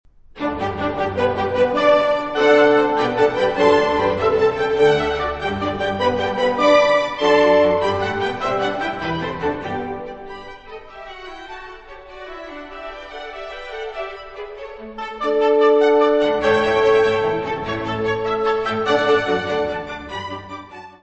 Music Category/Genre:  Classical Music
Allegro.